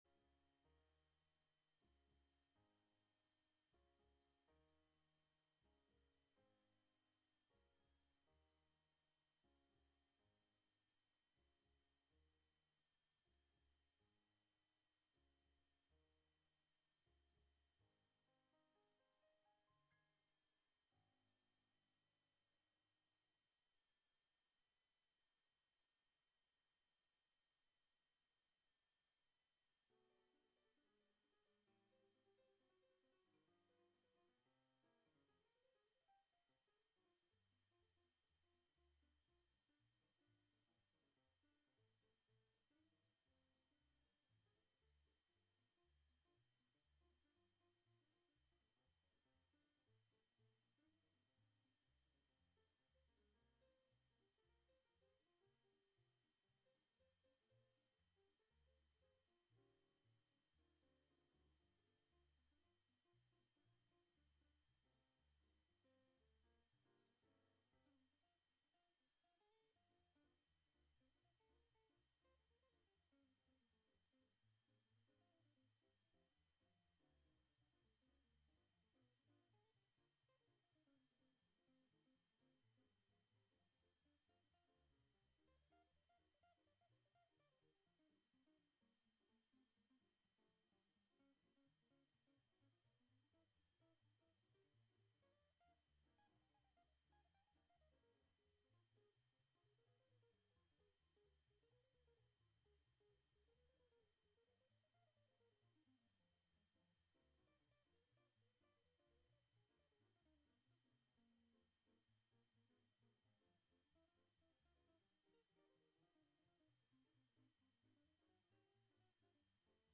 Live from The Flow Chart Foundation: This Land (Audio) Dec 07, 2024 shows Live from The Flow Chart Foundation Bard Vocalists in a recital of songs about American land and the envrionment.